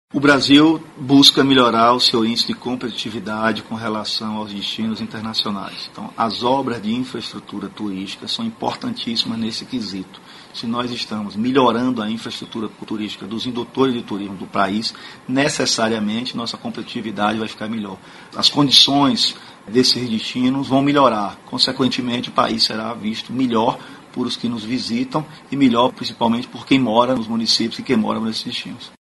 aqui para ouvir declaração do secretário Fábio Mota sobre como obras do tipo melhoram a competitividade turística do país.